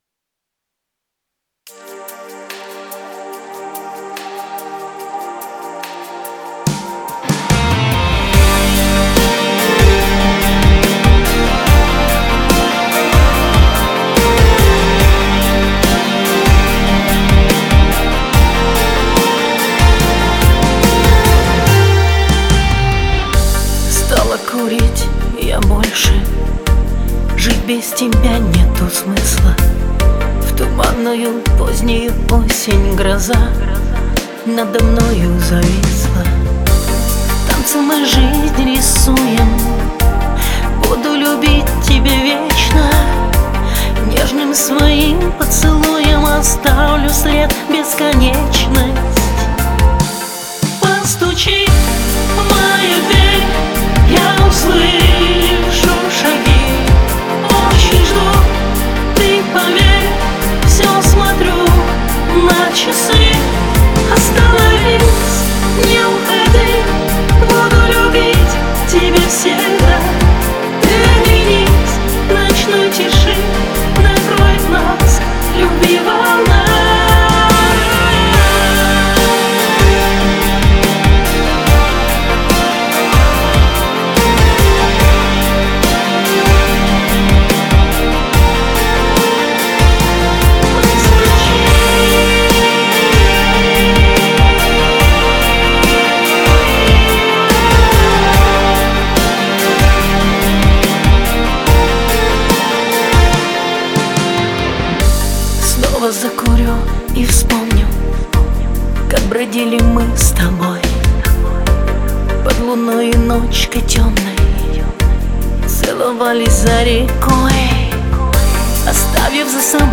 Альбом: шансон